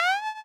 meow.wav